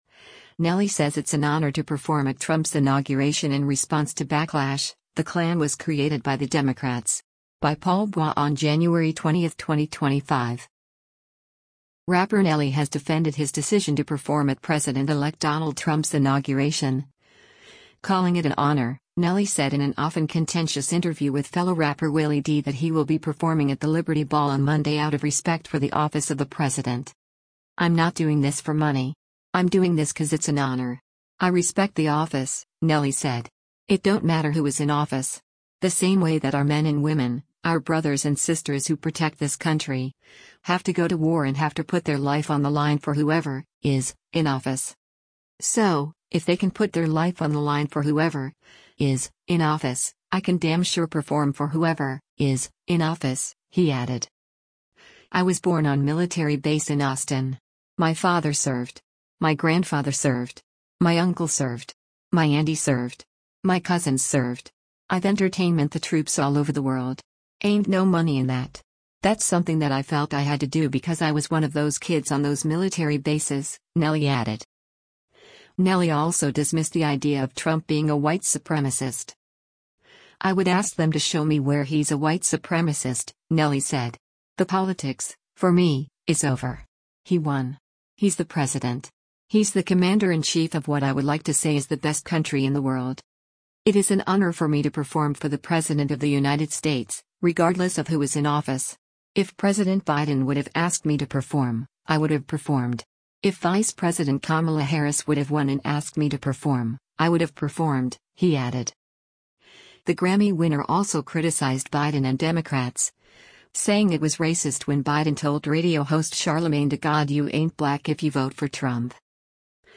Rapper Nelly has defended his decision to perform at President-elect Donald Trump’s inauguration, calling it “an honor.” Nelly said in an often contentious interview with fellow rapper Willie D that he will be performing at the Liberty Ball on Monday out of respect for the office of the president.
The conversation later changed when host Willie D called America a “Klan country.”